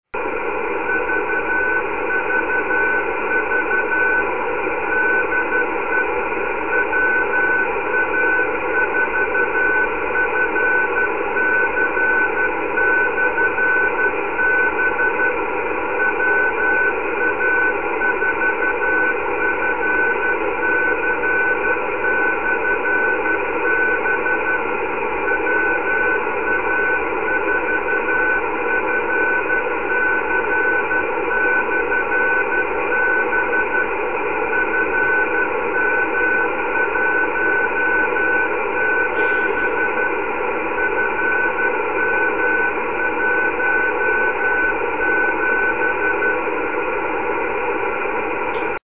New recording of 6 meter beacon
It’s interesting to know that the two watt signal made it that far.   It is unusual for it to be heard via groundwave propagation at that distance.